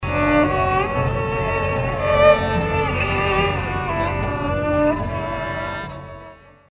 seasonal sounds of the Bartlesville Symphony Orchestra's String Quartet and the traditional countdown, the ninth annual Christmas In The Ville celebration at the Santa Fe Depot officially opened Sunday night.